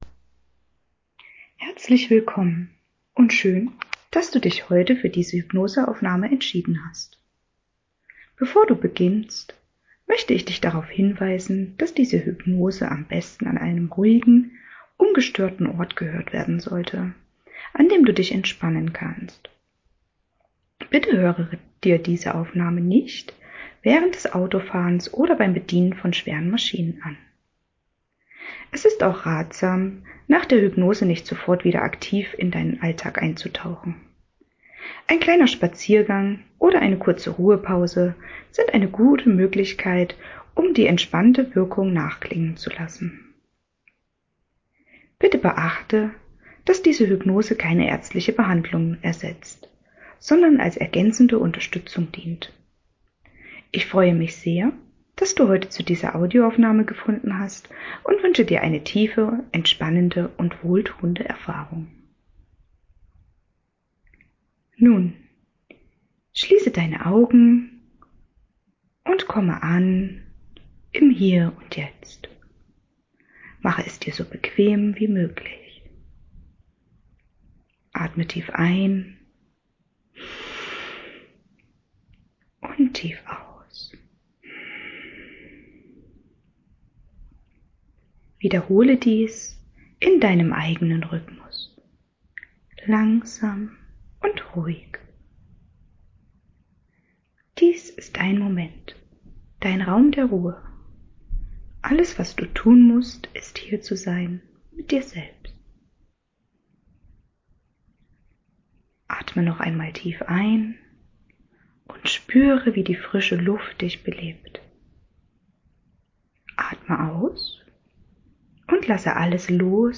Selbsthypnose für Zuhause